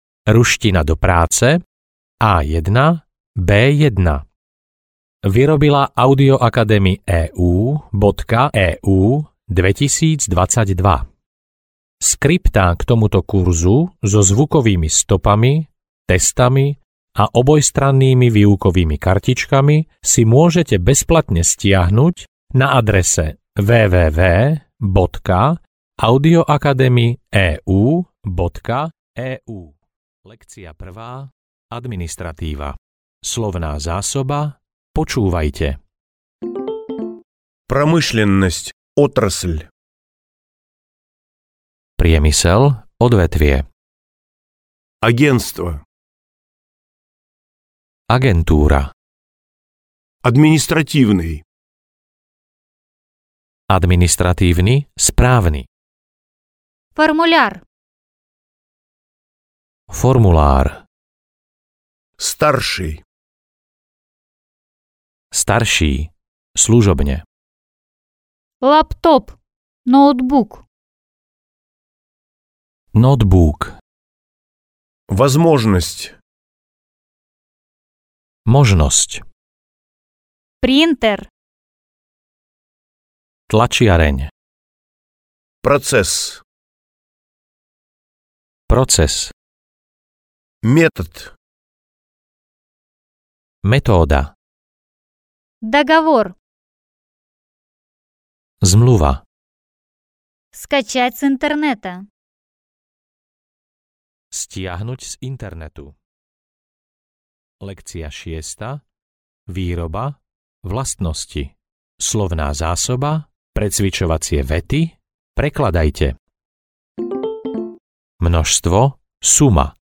Obchodná ruština A1-B1 audiokniha
Ukázka z knihy